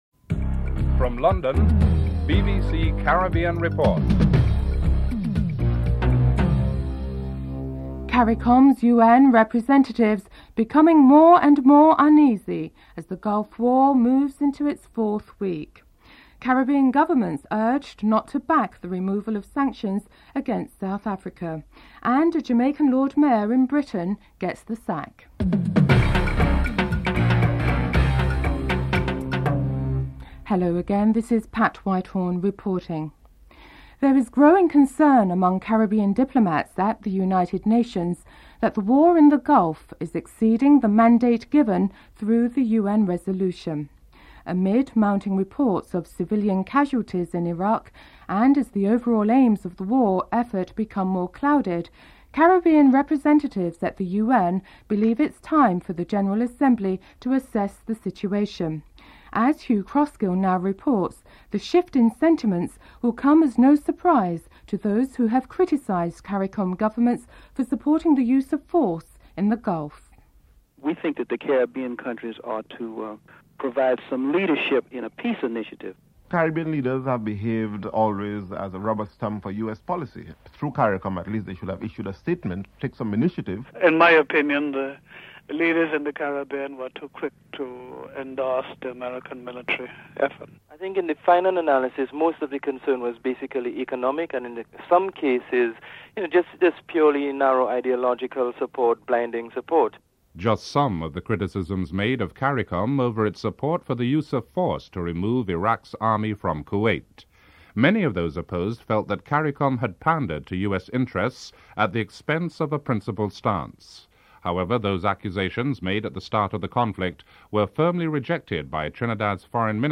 Segment 2: Contains clips with criticisms made about Caribbean leaders supporting the Gulf War.